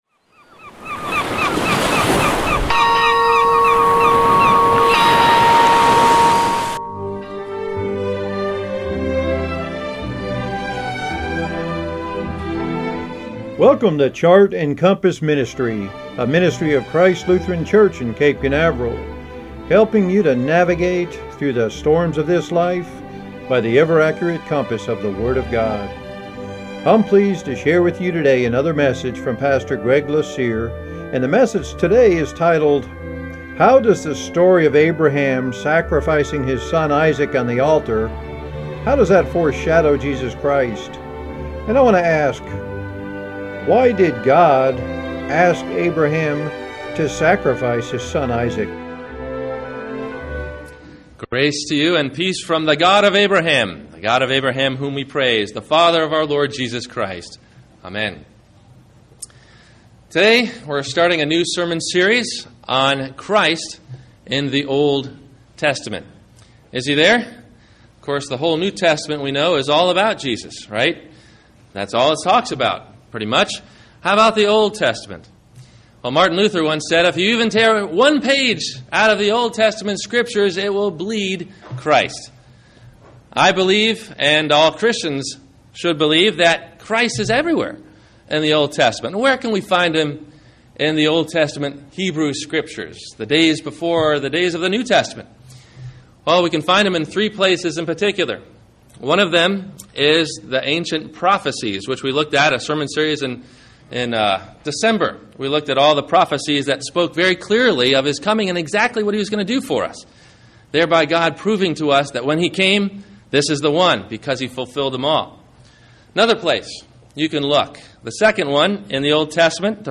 How does the story of Abraham sacrificing his son Issac on the Altar, foreshadow Jesus Christ? – WMIE Radio Sermon – March 07 2016